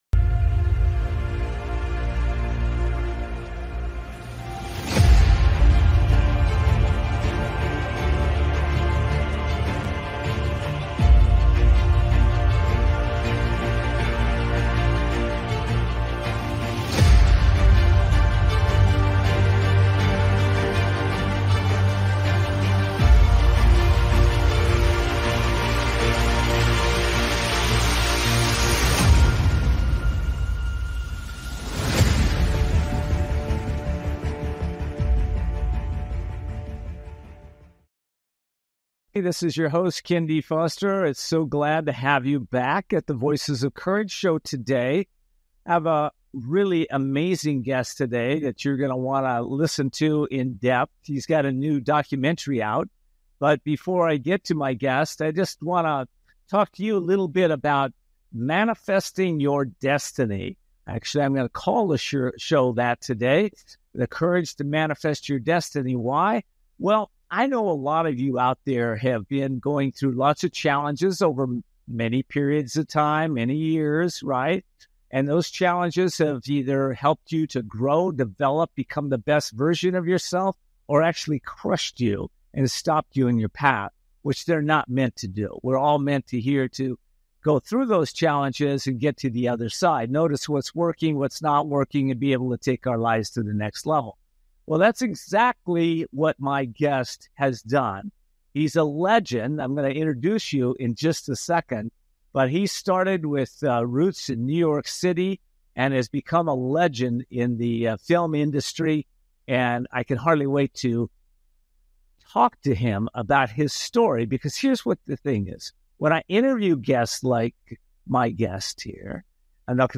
Talk Show Episode
Guest, Glynn Turman, The Courage to Manifest Destiny
Today's guest is legendary actor Glynn Turman.